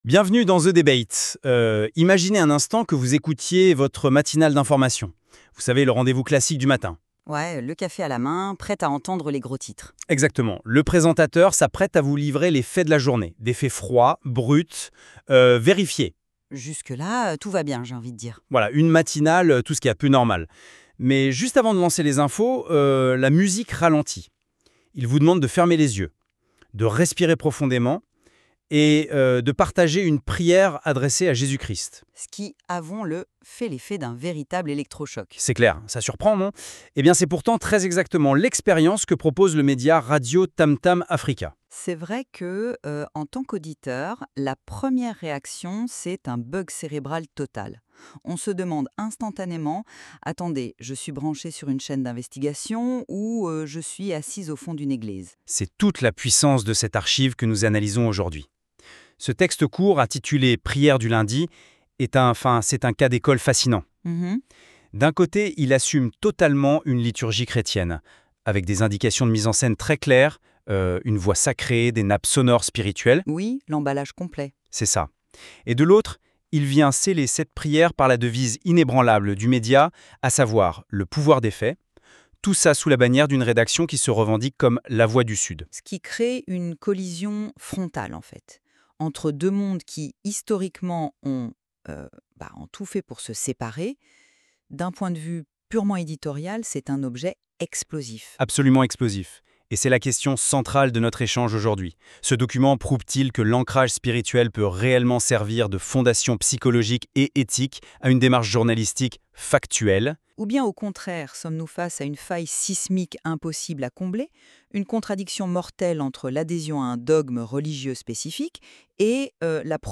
PRIÈRE